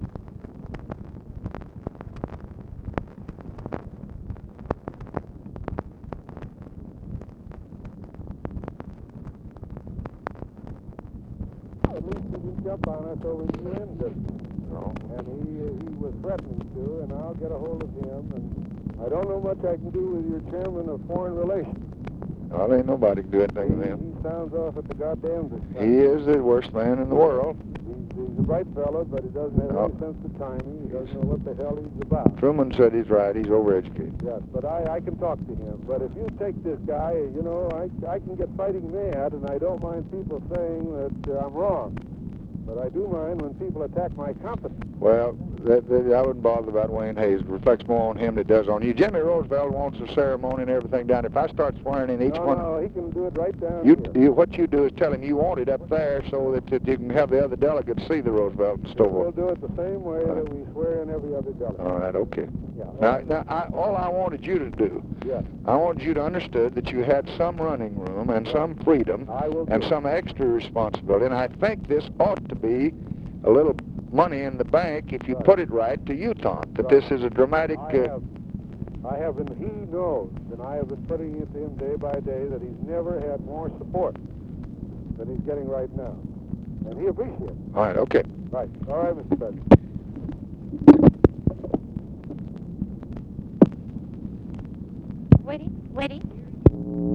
Conversation with ARTHUR GOLDBERG, September 18, 1965
Secret White House Tapes